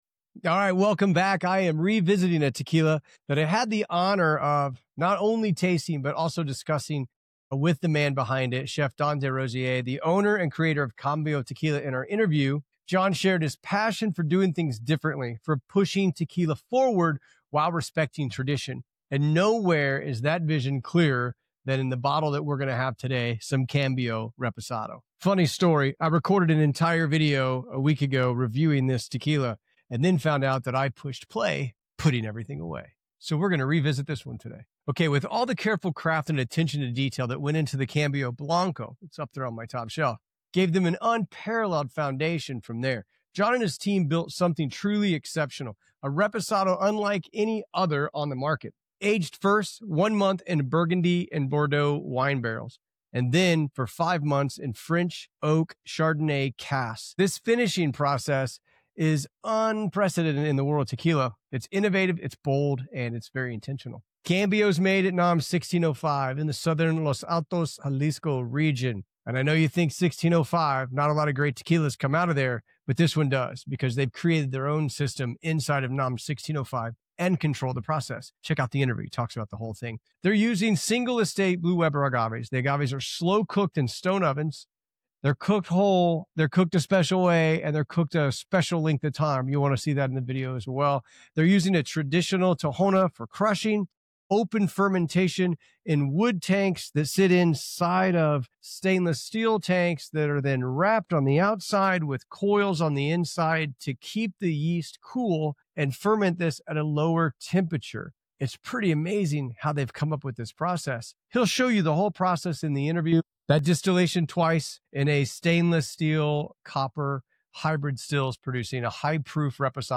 In this review, I explore the unique barrel aging of Cambio Reposado Tequila—finished in Burgundy, Bordeaux, and French Oak Chardonnay casks.